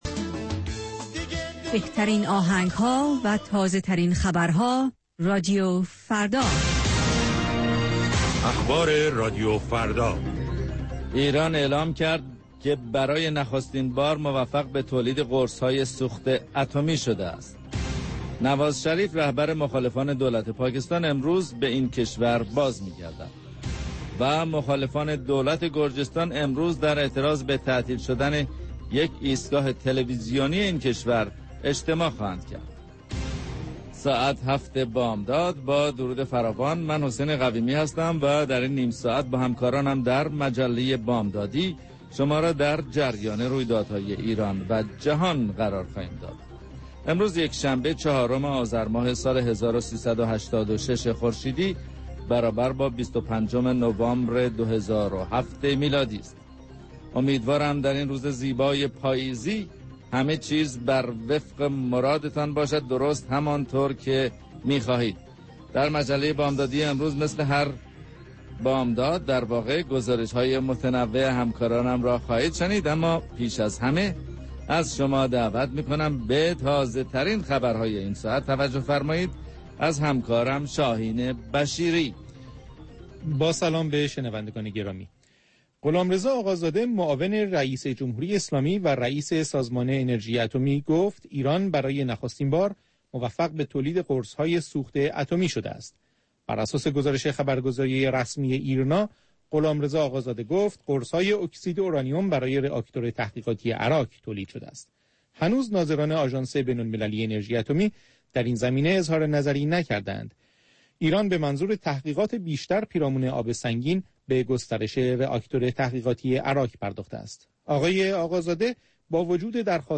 گزارشگران راديو فردا از سراسر جهان، با تازه ترين خبرها و گزارش ها، مجله ای رنگارنگ را برای شما تدارک می بينند. با مجله بامدادی راديو فردا، شما در آغاز روز خود، از آخرين رويدادها آگاه می شويد.